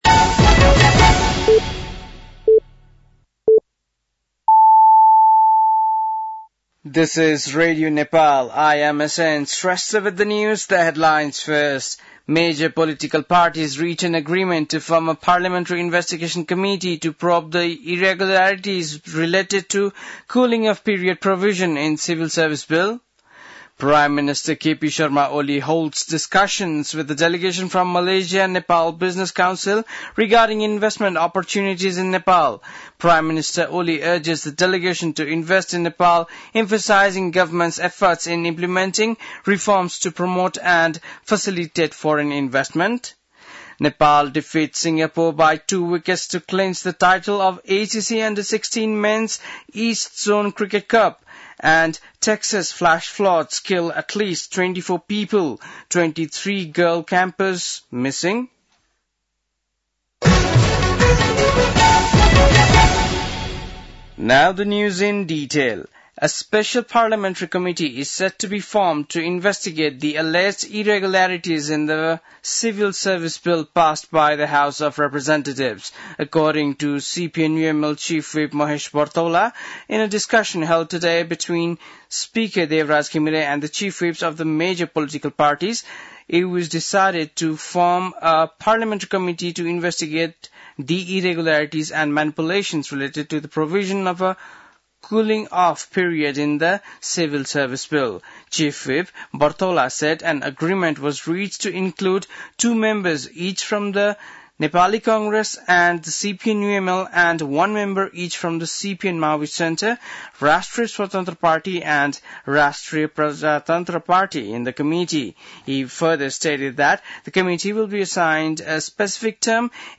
बेलुकी ८ बजेको अङ्ग्रेजी समाचार : २१ असार , २०८२
8pm-news-.mp3